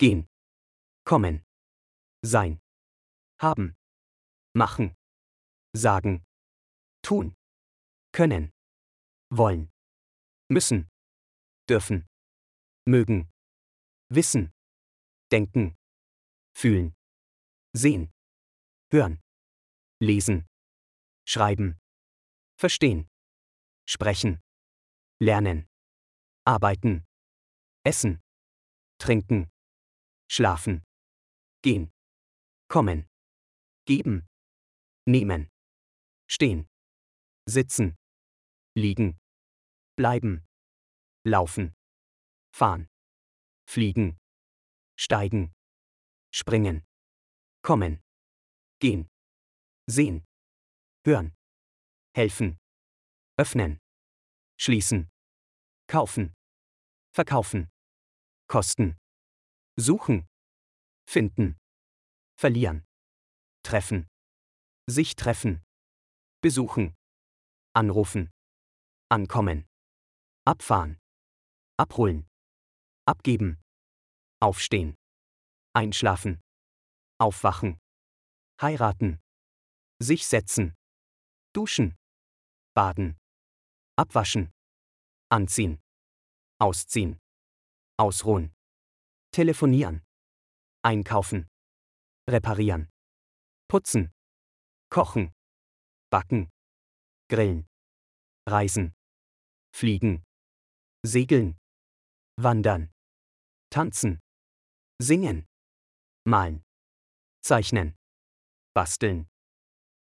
سنضع لكم لفظ كل كلمة مكتوبة بالعربي وايضاً صوتياً لأن كلمات المانية مكتوبة بالعربي تساعد المبتدئين في تعلم اللغة الألمانية بشكل أفضل وأسرع.